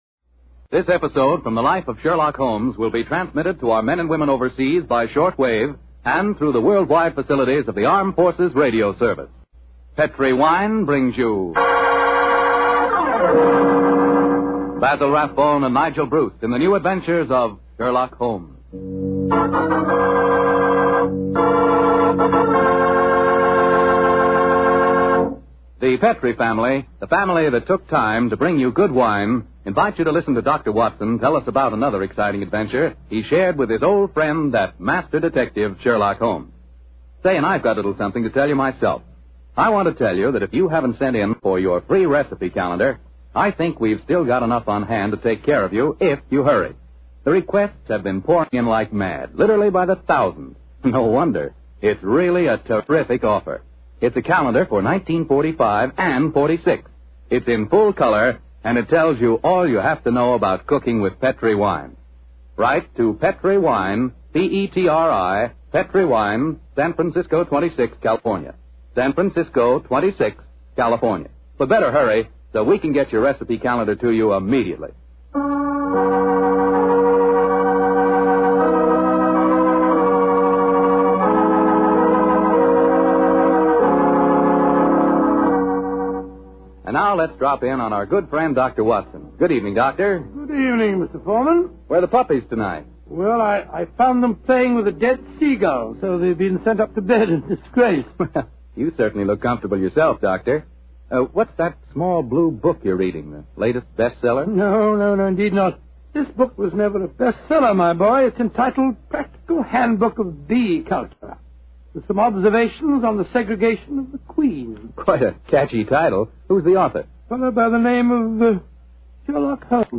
Radio Show Drama with Sherlock Holmes - The Notorious Canary Trainer 1945